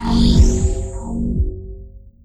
item_spawn.wav